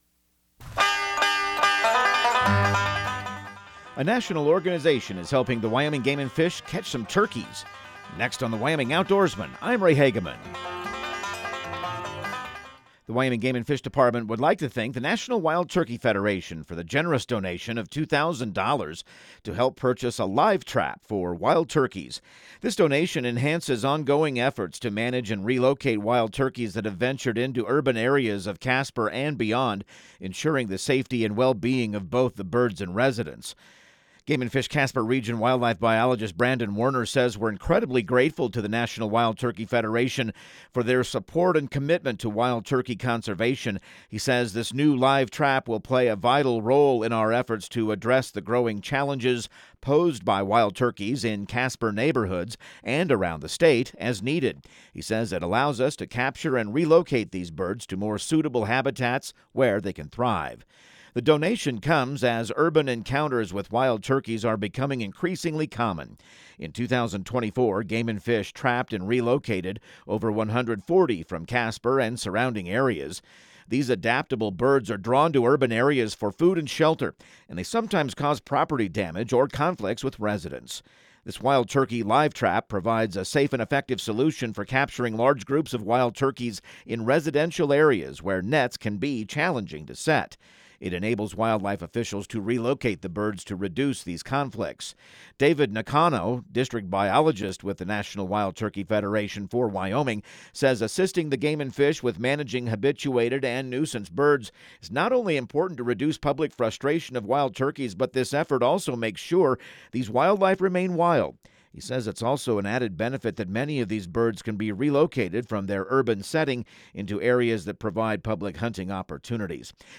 Radio news | Week of February 10